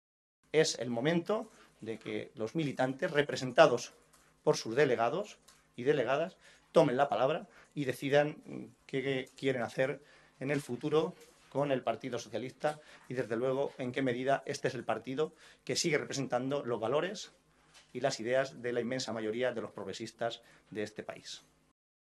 Así se expresaba el secretario de Organización regional, José Manuel Caballero, esta tarde en rueda de prensa, tras la celebración de la Ejecutiva regional.